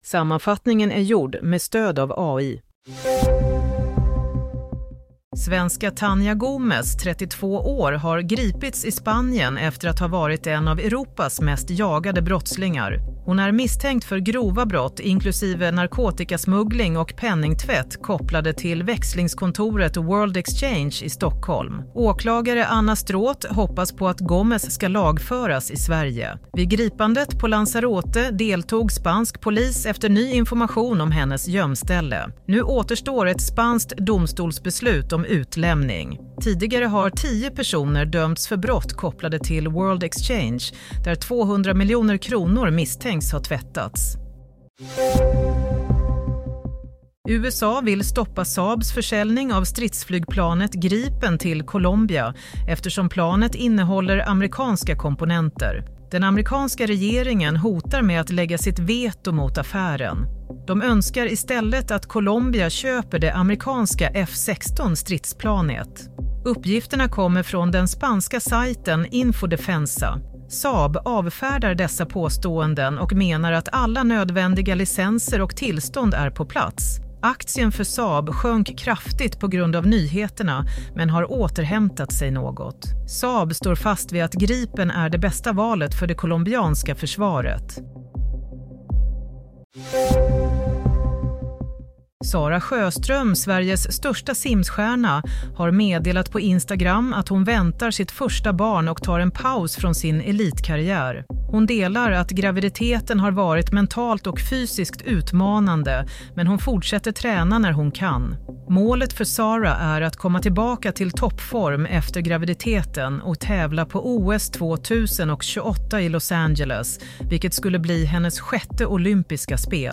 Nyhetssammanfattning - 26 februari 16:00